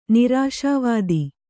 ♪ nirāśā vādi